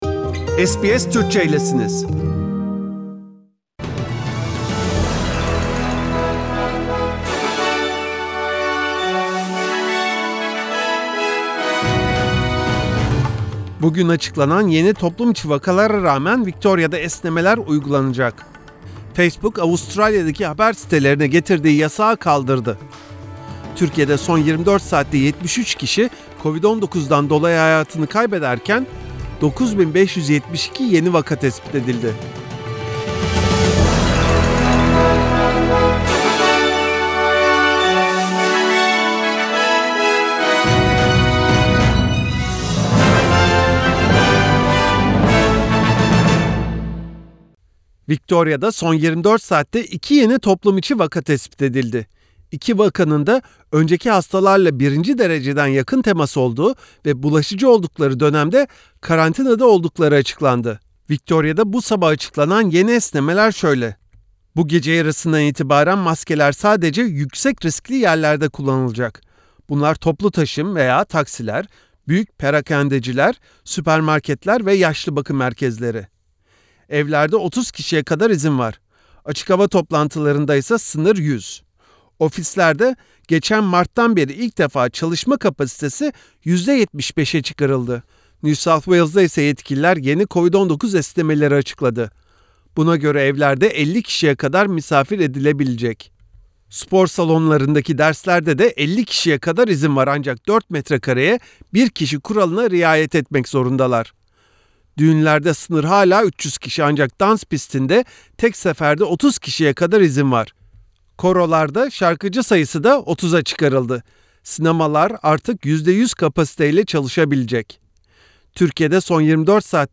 SBS Türkçe Haberler 26 Şubat